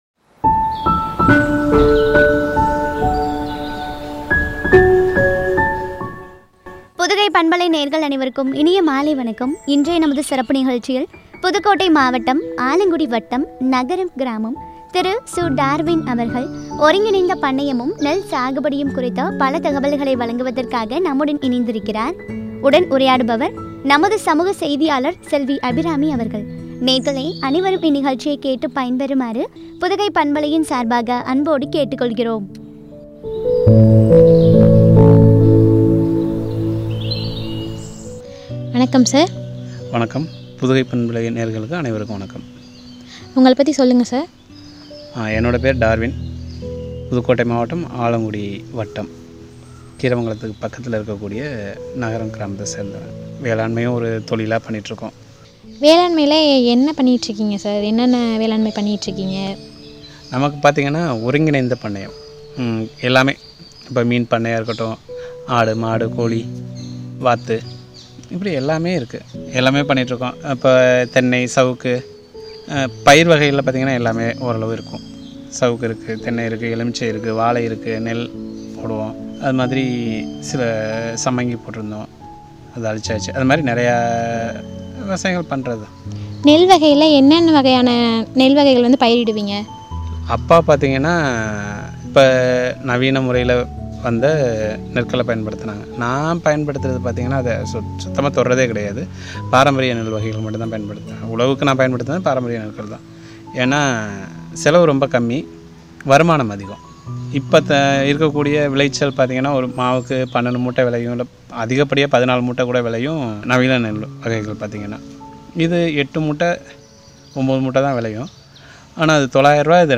நெல் சாகுபடியும் பற்றிய உரையாடல்.